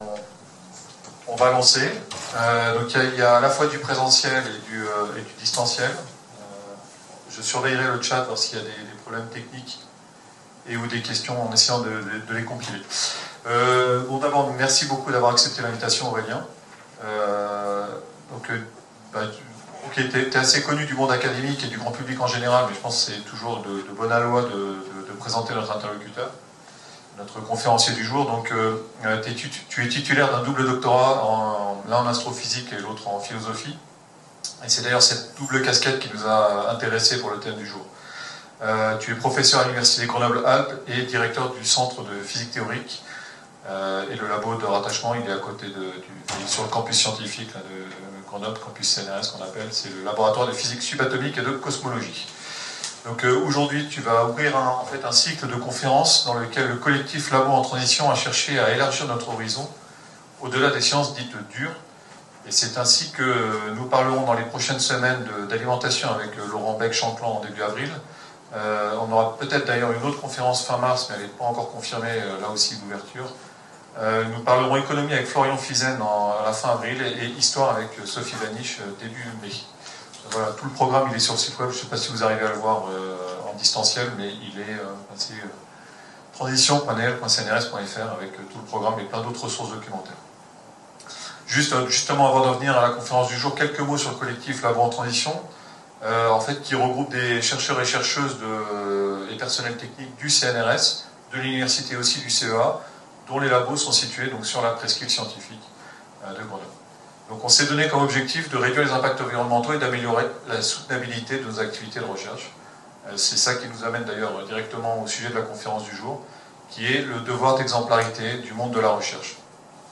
Conférence d'Aurélien Barrau